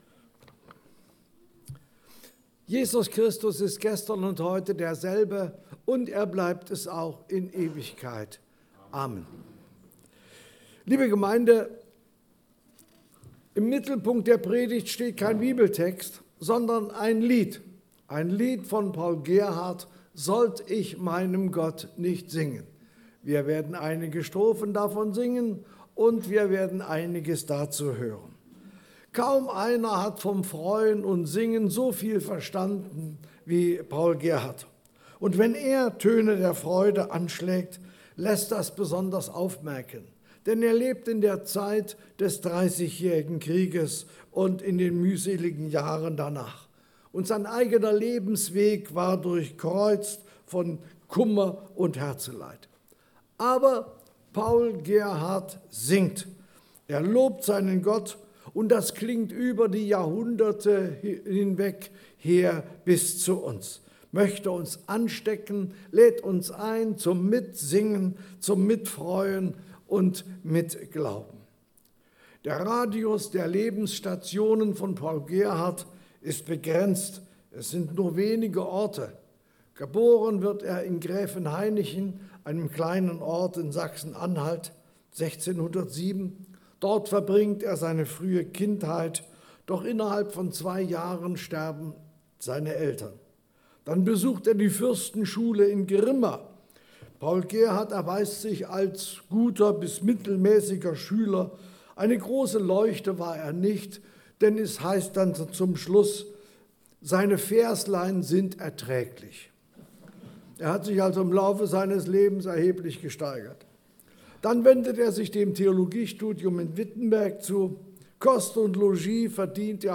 Dienstart: Gottesdienst Themen: Lieder des Evangelisches Gesangbuch « Matthäus 28